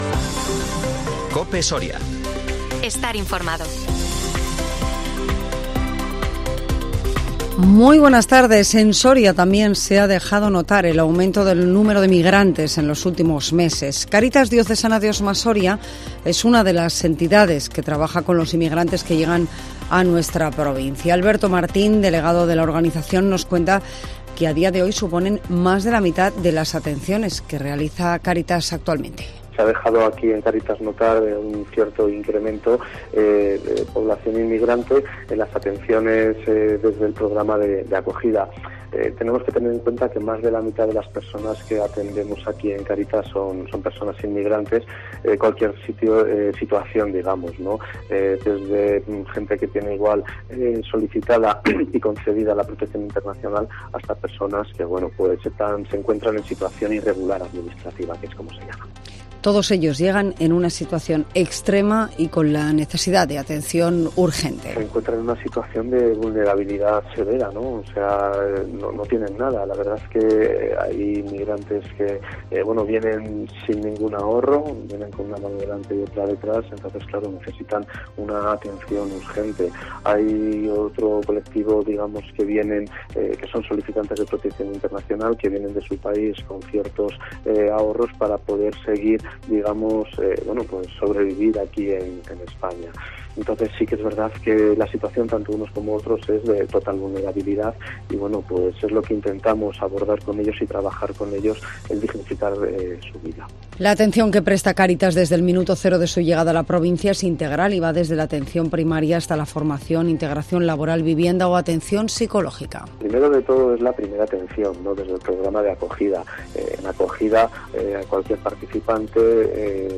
INFORMATIVO MEDIODÍA COPE SORIA LUNES 23 OCTUBRE 2023